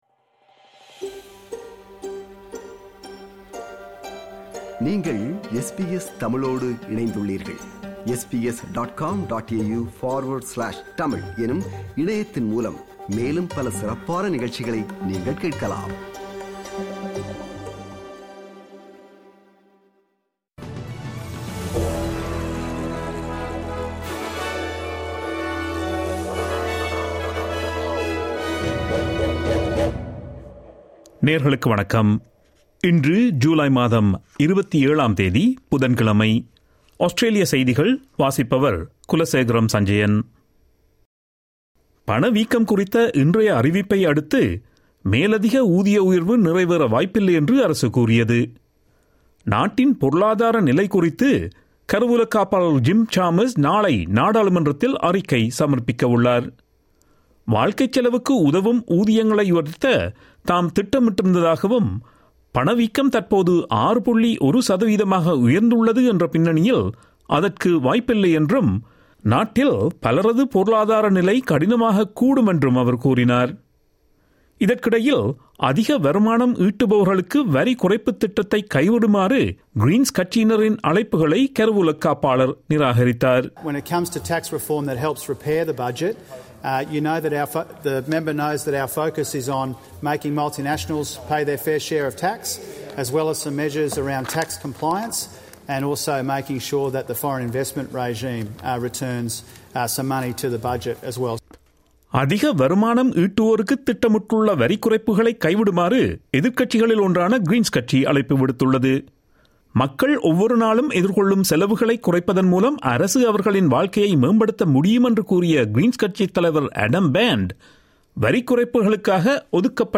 Australian news bulletin for Wednesday 27 July 2022.